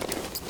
tac_gear_15.ogg